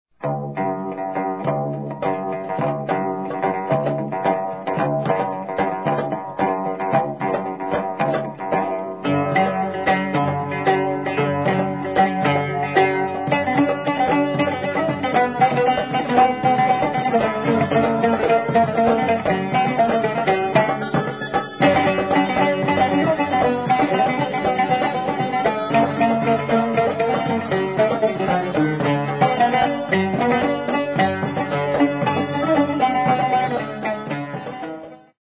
traditional Near Eastern style
Ensemble, with Oud